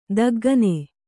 ♪ daggane